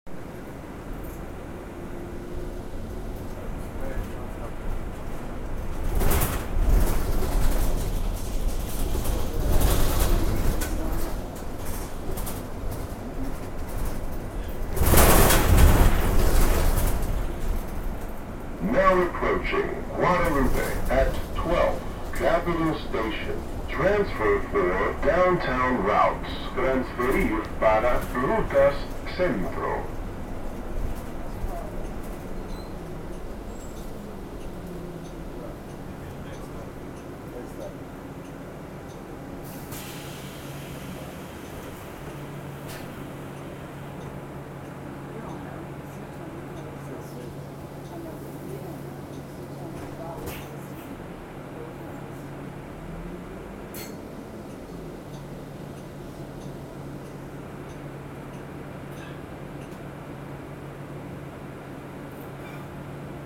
when the doors open, i'm supposed to hear the bus number announcement (so i know what bus to get in).